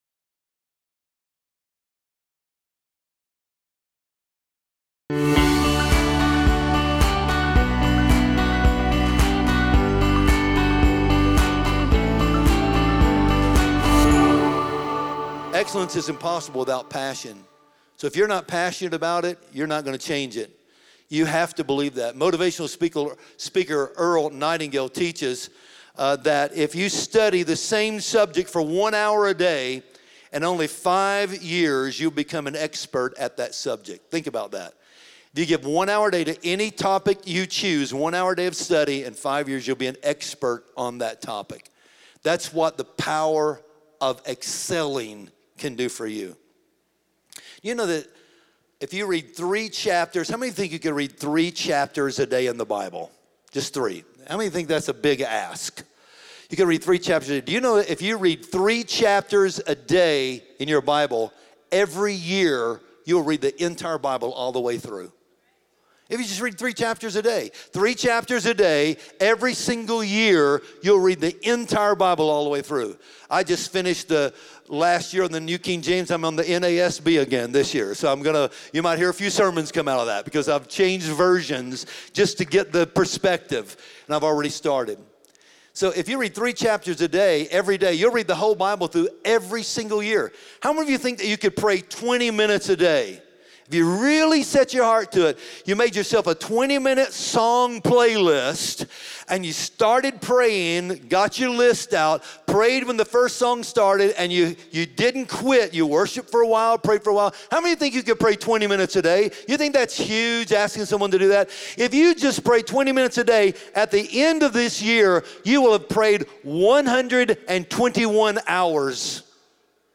Discover how living in God’s favor daily is connected to excellence, obedience, and spiritual discipline in this powerful sermon segment.